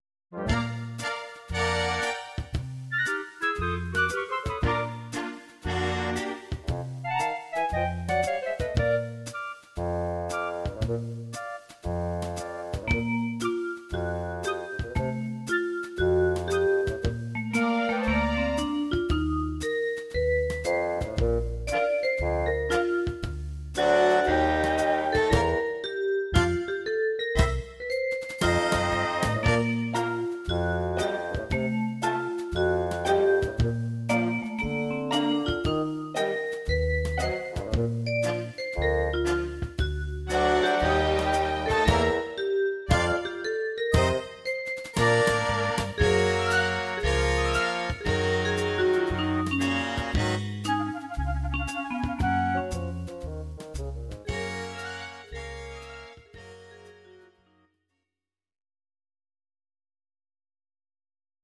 These are MP3 versions of our MIDI file catalogue.
Your-Mix: Jazz/Big Band (731)